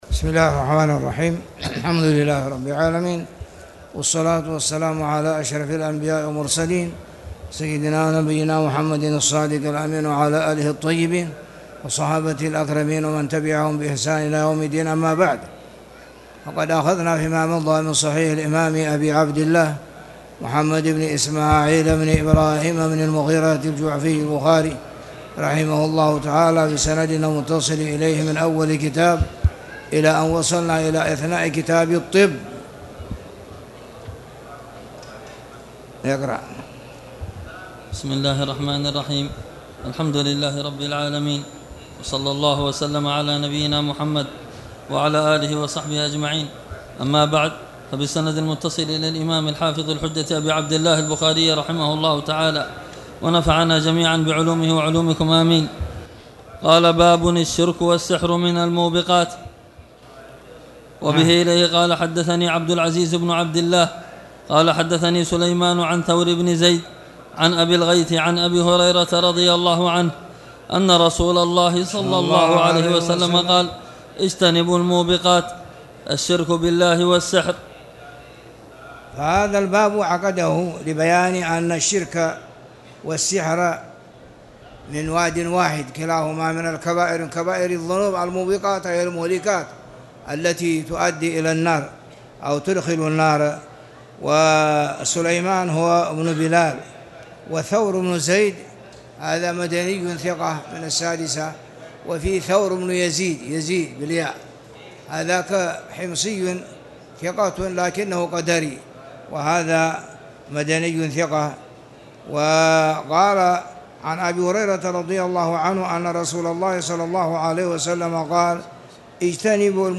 تاريخ النشر ١٠ ربيع الثاني ١٤٣٨ هـ المكان: المسجد الحرام الشيخ